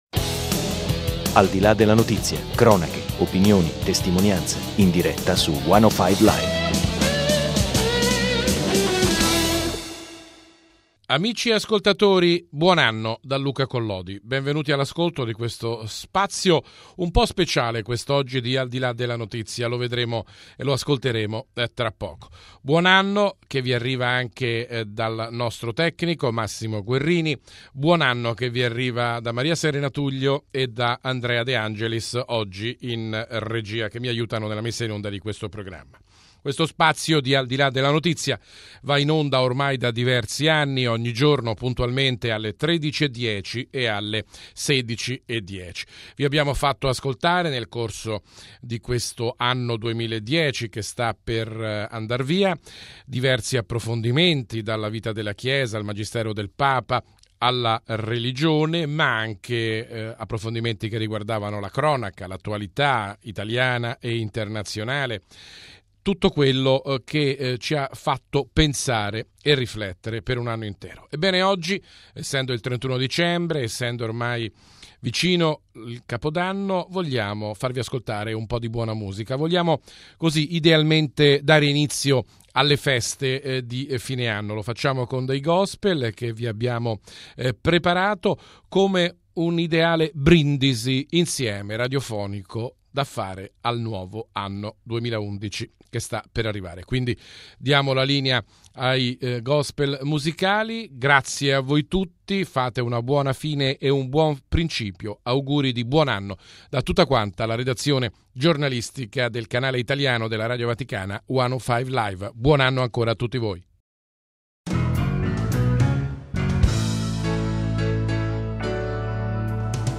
Oggi, lo spazio di Al di là della Notizia, da sempre dedicato agli approfondimenti dell'attualità, festeggia con voi l'arrivo del nuovo anno con un ideale brindisi musicale radiofonico.